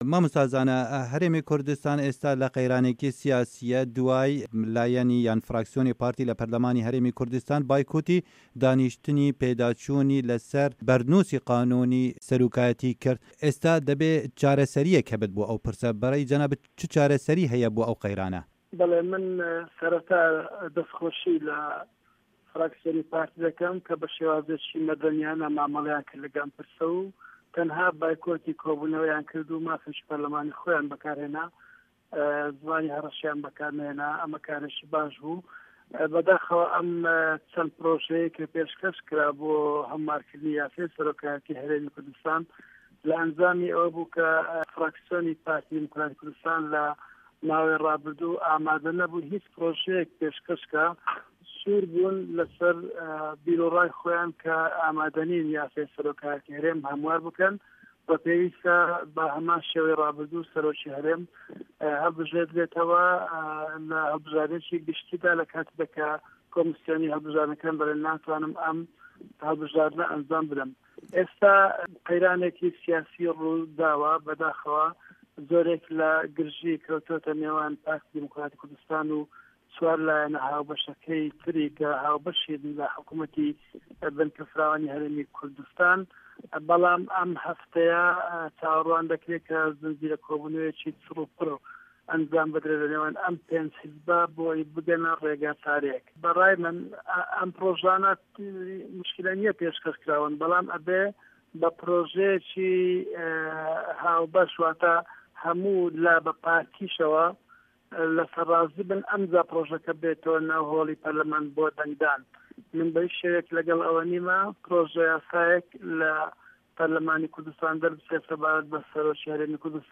گفتوگۆ